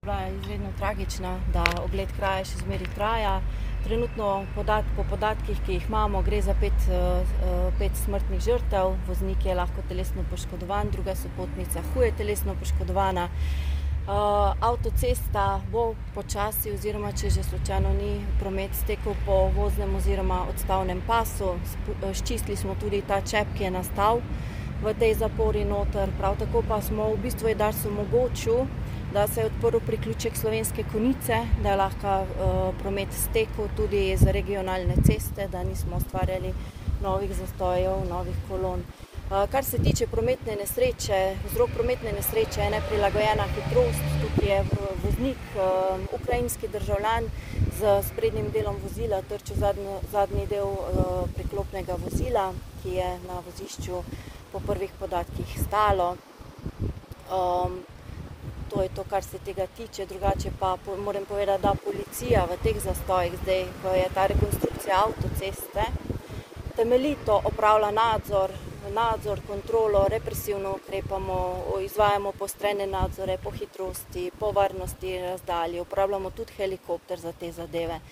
izjava PU Celje.mp3